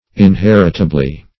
inheritably - definition of inheritably - synonyms, pronunciation, spelling from Free Dictionary Search Result for " inheritably" : The Collaborative International Dictionary of English v.0.48: Inheritably \In*her"it*a*bly\, adv.